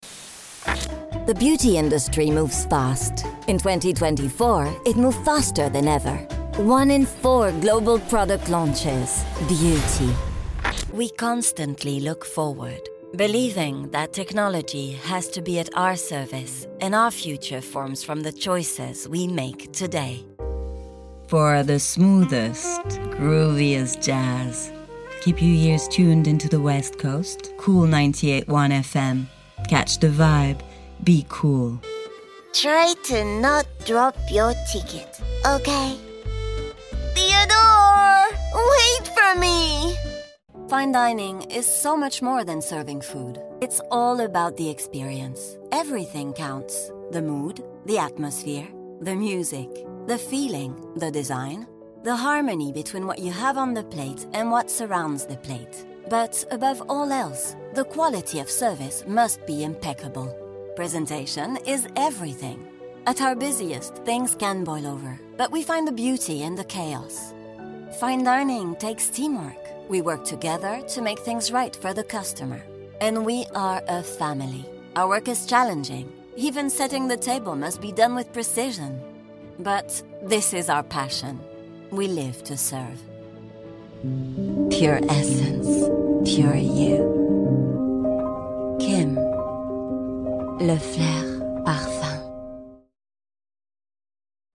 Commercial Demo
I can also perform in English with a more or less pronounced French accent, as well as Spanish with either South American or neutral accents.
Home Studio Setup
Microphone : RODE NT1-A